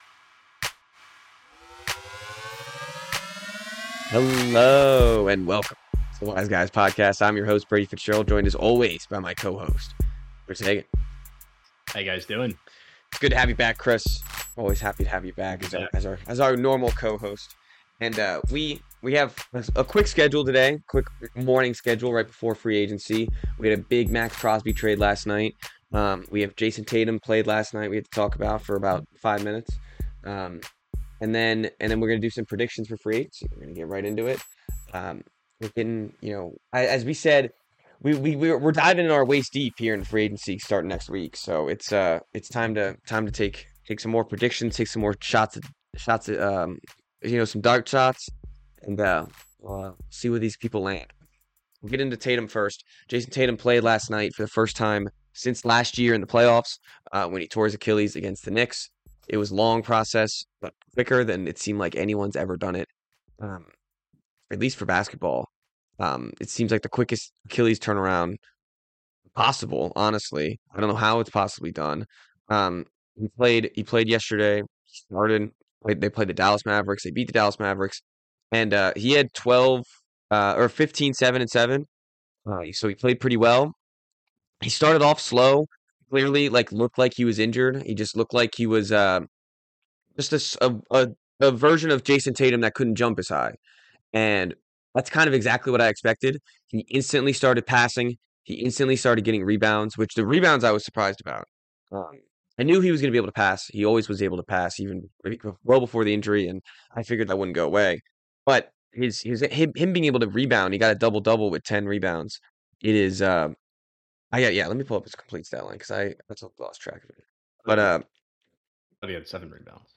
No scripts.